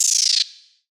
Open Hats
DDW Open Hat 3.wav